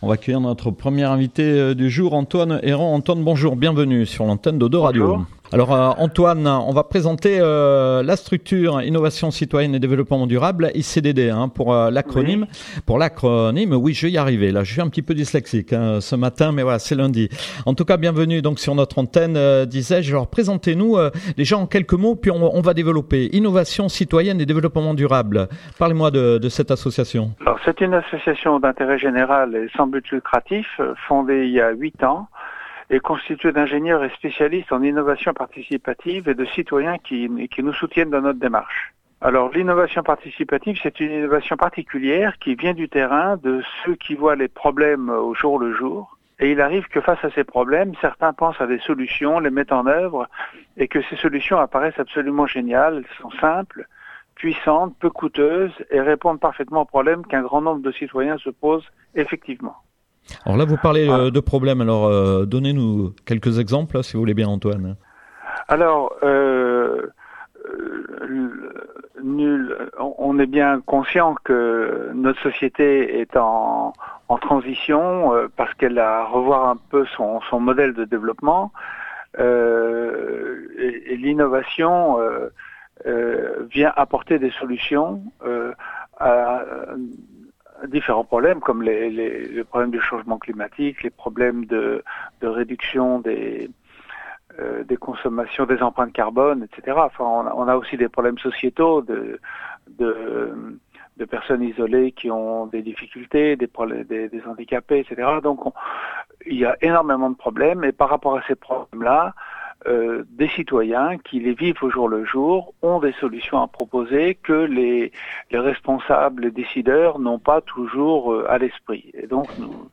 Nouvel interview GNIAC / O2Radio